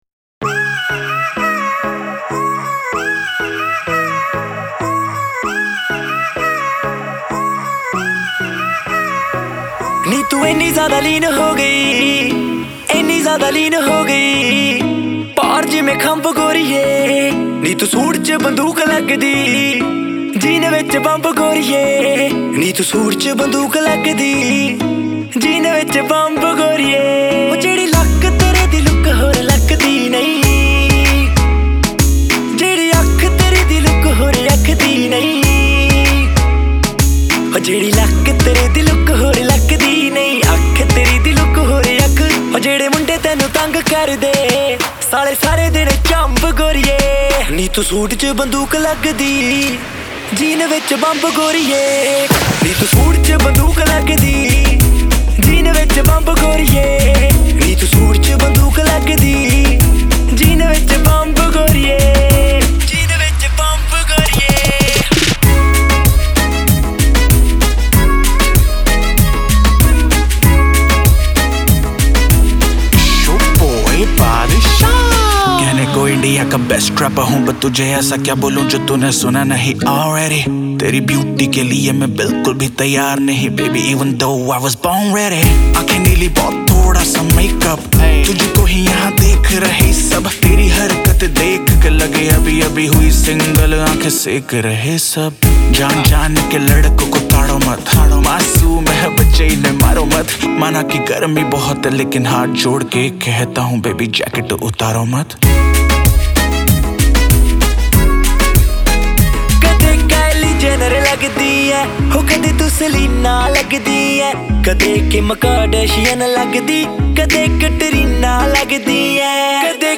رپ پاپ هندی